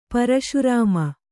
♪ paraśu rāma